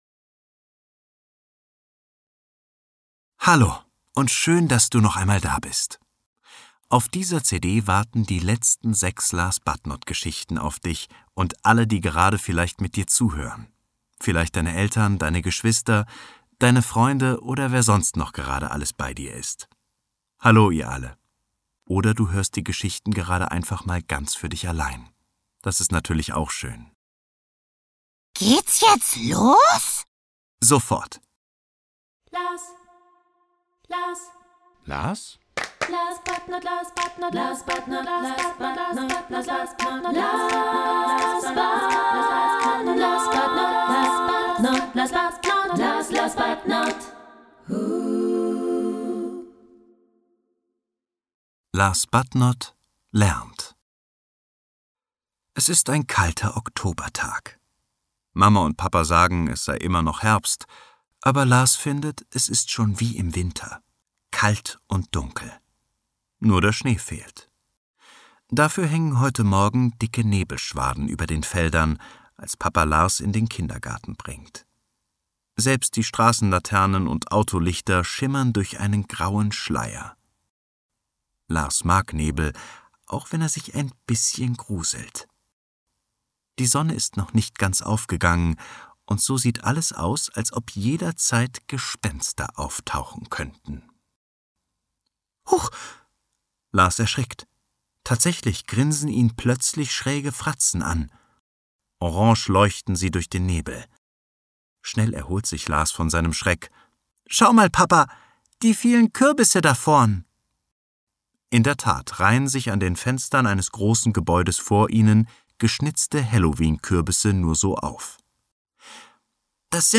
6 neue Geschichten erzählt für Kinder ab 3 Jahren
Schlagworte Coolness • Kinderhörbuch • Lesegeschichten • Mutmacher • Neugierde, • Phantasie • Selbstbewusstsein • Selbstvertrauen • Selbstwertgefühl